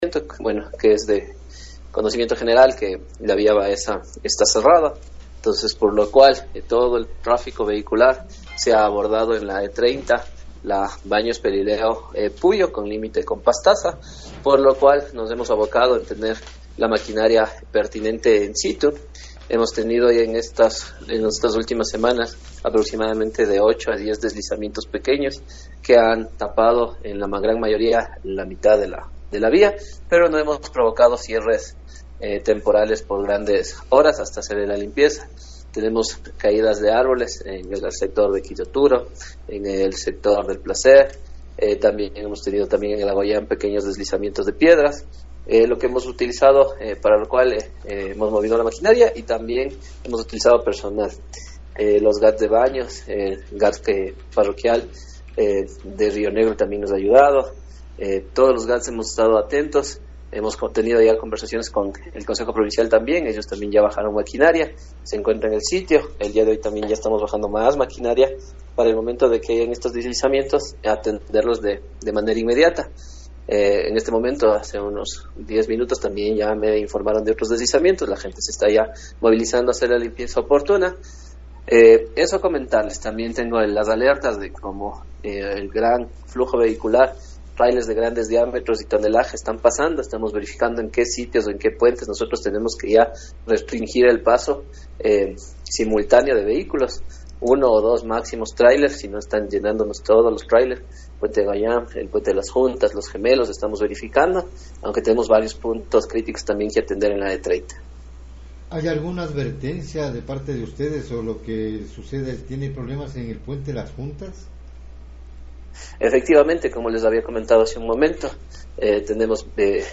director-mtop-tungurahua.mp3